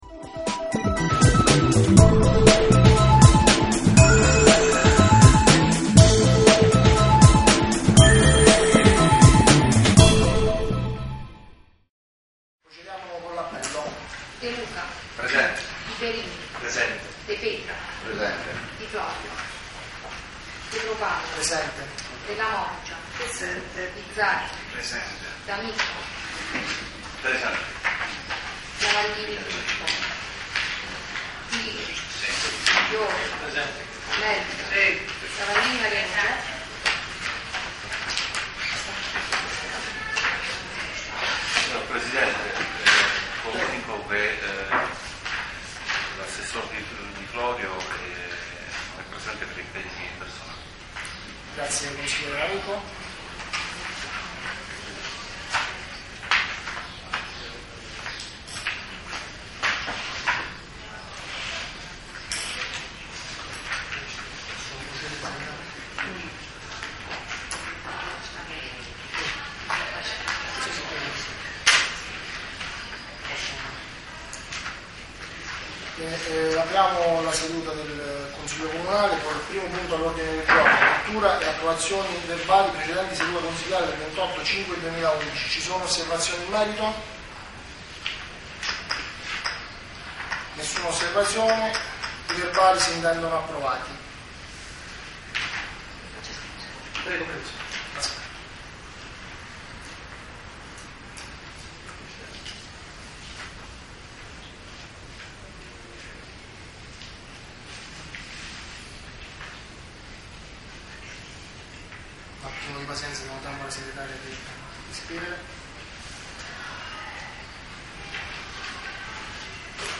Ascolta il Consiglio Comunale del 23 Giugno 2011